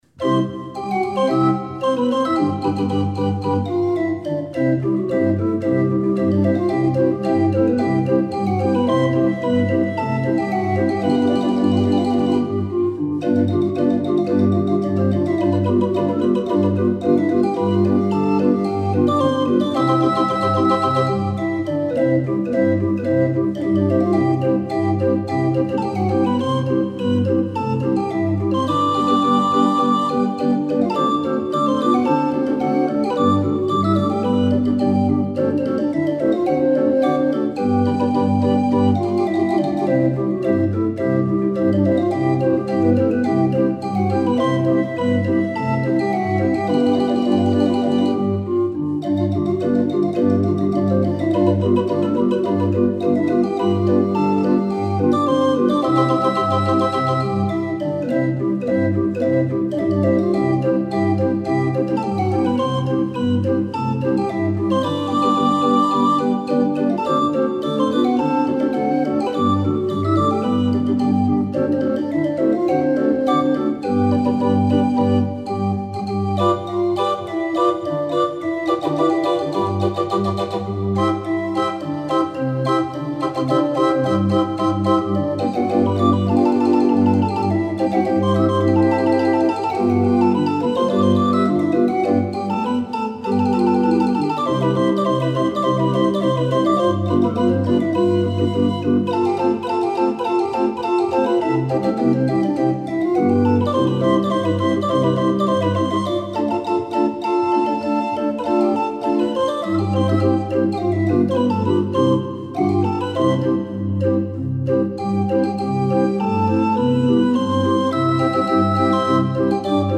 26 street organ